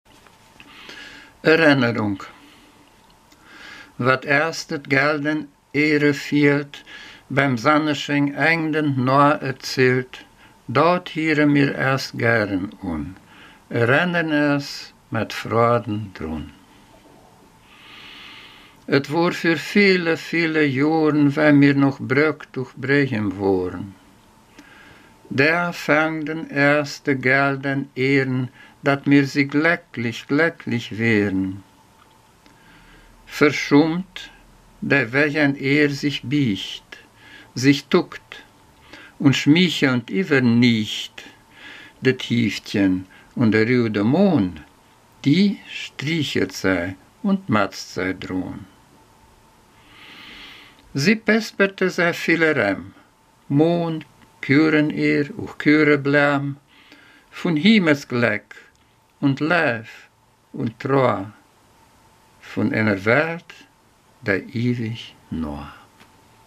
Ortsmundart: Mediasch